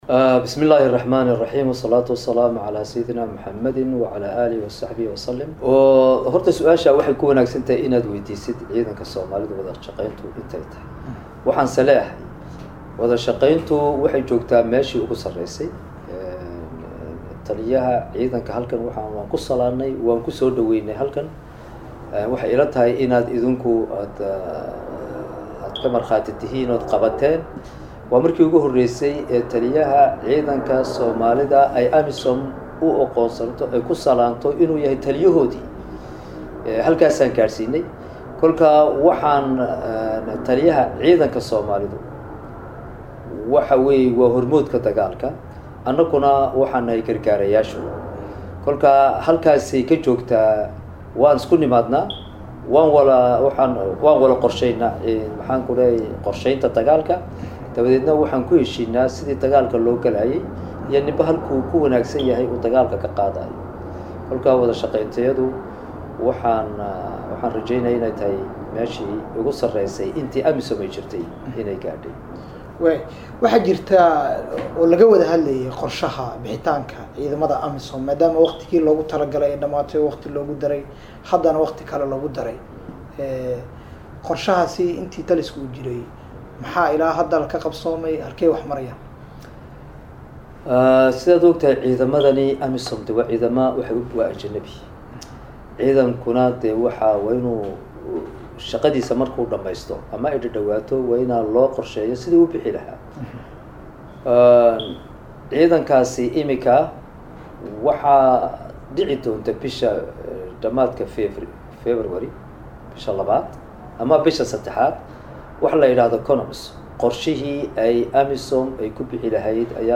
Taliyaha Ciidamada AMISOM ee Soomaaliya jooga Generalka Cismaan Nuur Subagle oo la hadlay Warbaahinta Qaranka ayaa faah faahin guulaha ay ka gaareen Dagaalka ay kula jiraan Maleeshiyaadka nabad iyo nolal diidka ah ee Al shabaab.
Waraysi-Taliyaha-Ciidanka-Amisom-Gen-Cismaan-Nuur-Subagle-.mp3